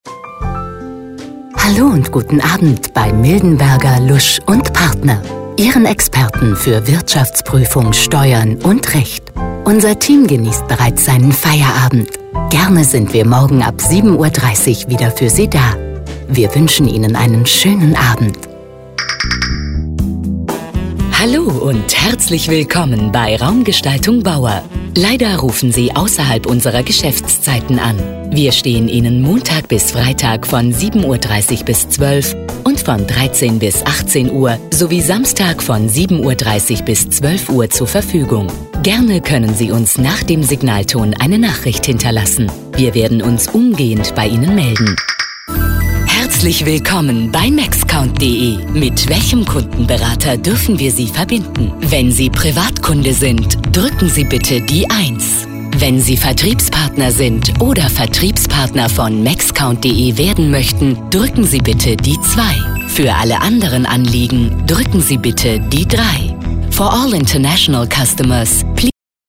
Anrufbeantworter-Ansage Sprecher - Synchronsprecher
🟢 Standard Sprecher